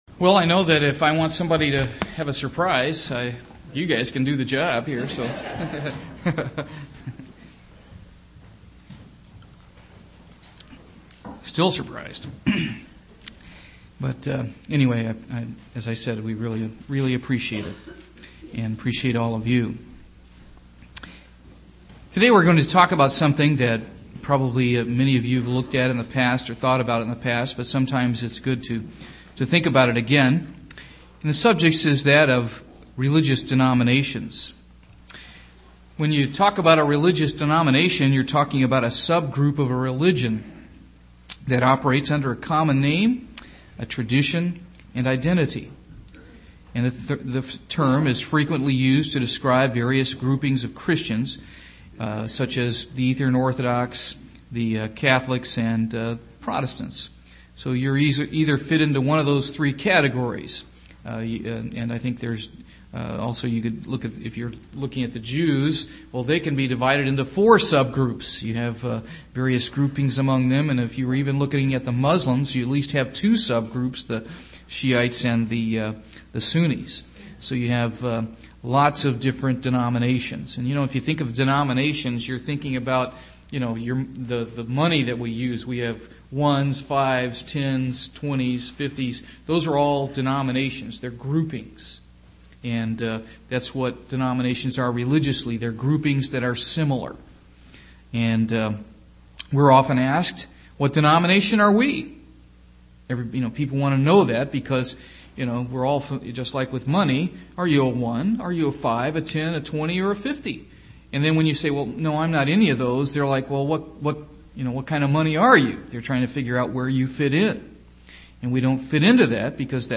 Given in Portsmouth, OH
UCG Sermon Studying the bible?